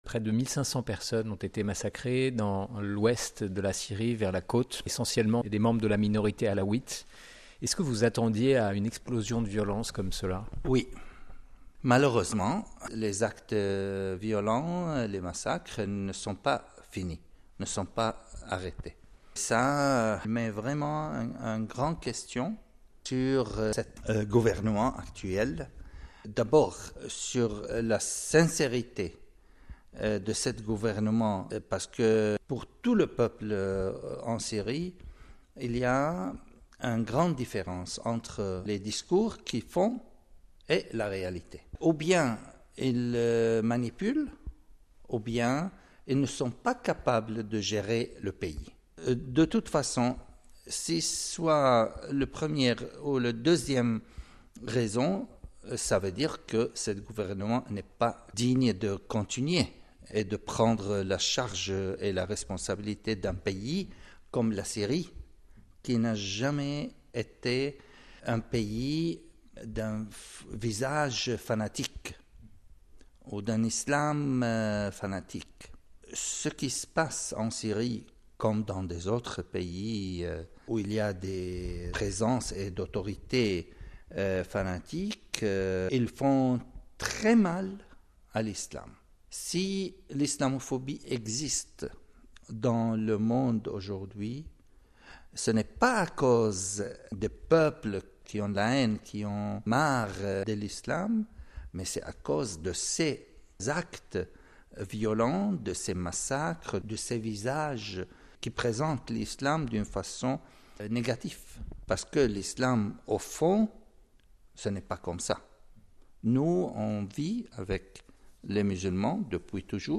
La situation en Syrie. Un entretien avec Mgr Jacques Mourad sur “Vatican News”.